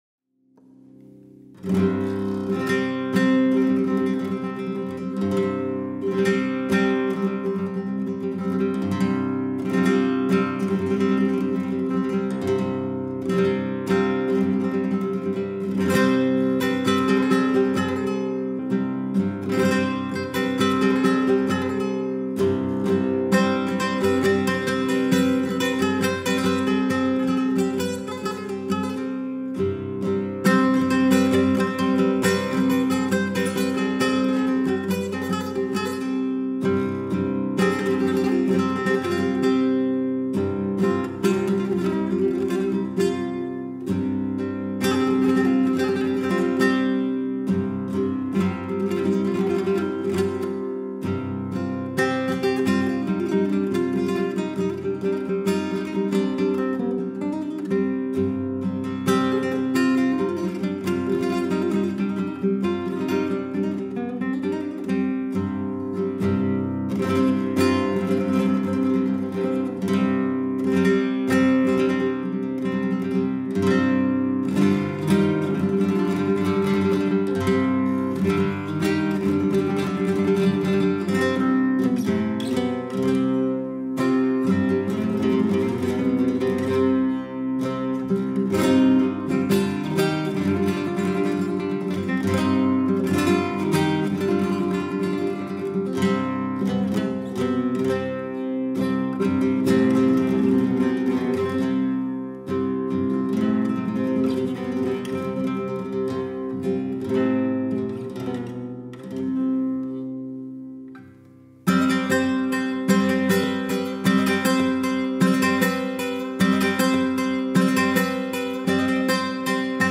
Persian Guitar